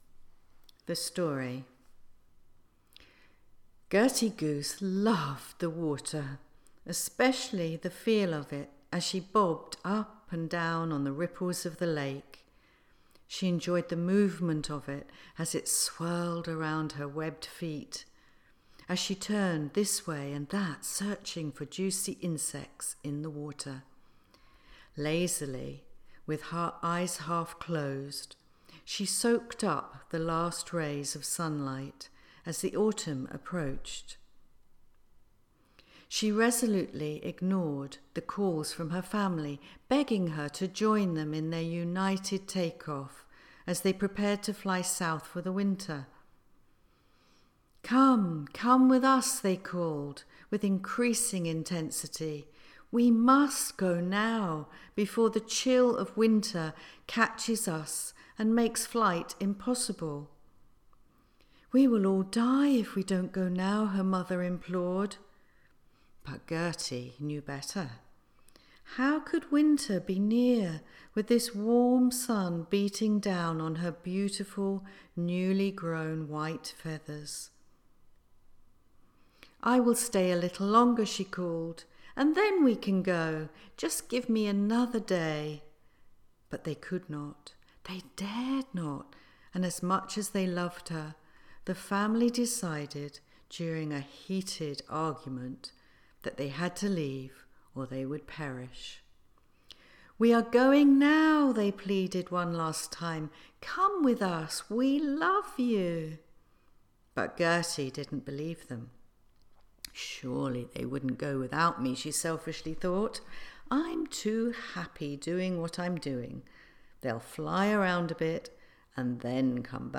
There is a sample of my first audiobook for young children at the top of the page.
Gertie-Goose-the-Story.mp3